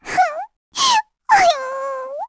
Lilia-Vox_Sad_kr.wav